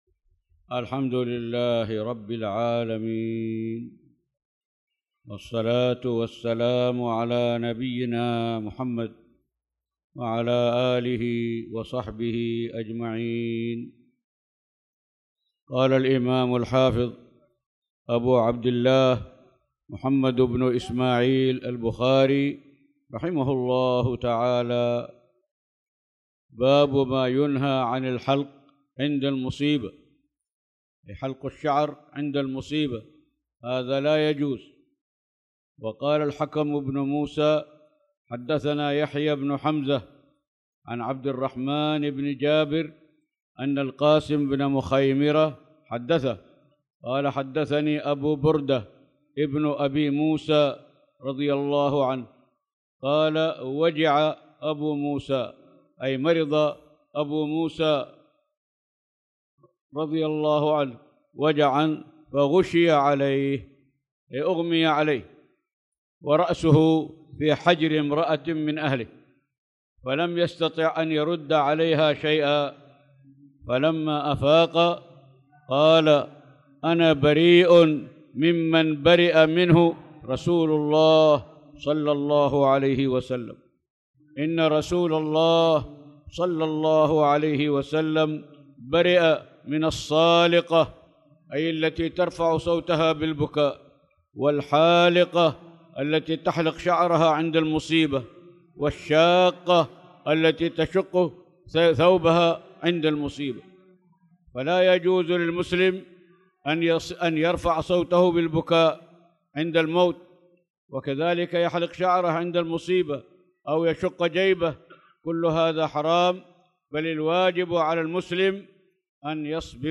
تاريخ النشر ٢١ ذو القعدة ١٤٣٧ هـ المكان: المسجد الحرام الشيخ